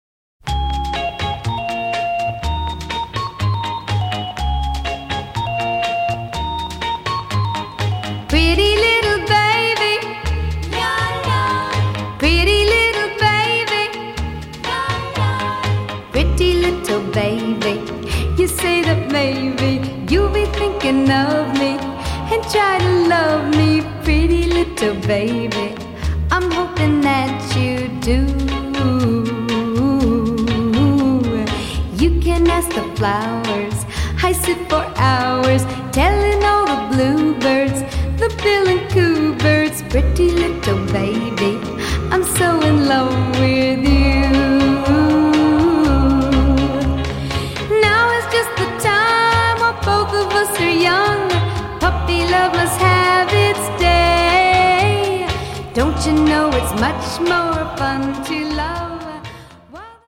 AI Song Cover